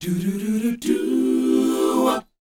DOWOP D 4A.wav